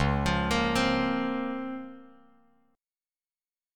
DbM13 chord